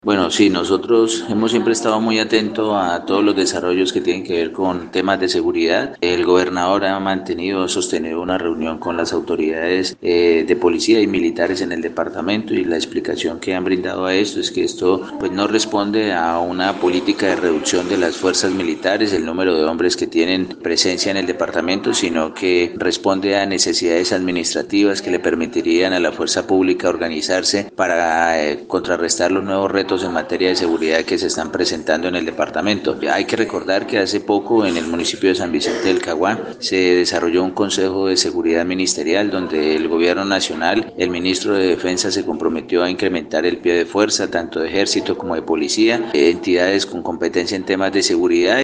Arturo Perdomo Granja, secretario de gobierno departamental, explicó que al contrario de lo dicho inicialmente, el compromiso del ministerio de defensa y los altos mandos de las fuerzas militares, es el de fortalecer la fuerza pública con la creación de fuerzas de despliegue rápido, FUDRA.
SECGOB_ARTURO_PERDOMO_GRANJA_TEMA_-_copia.mp3